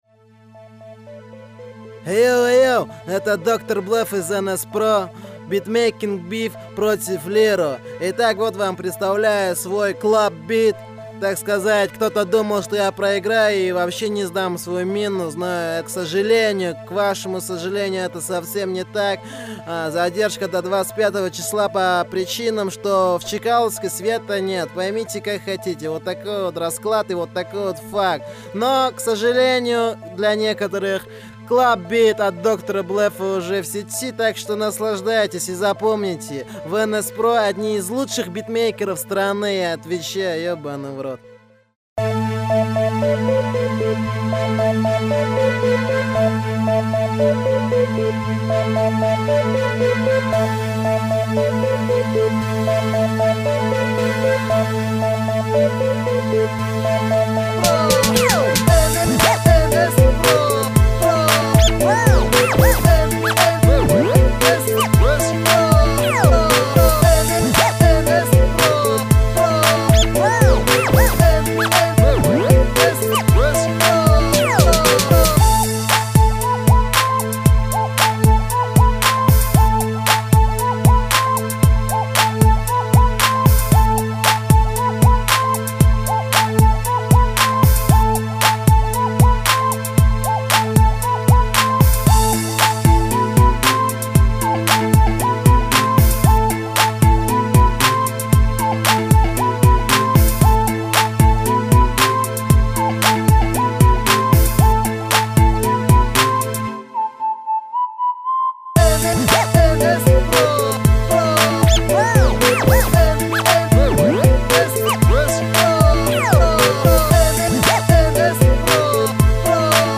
beatmaker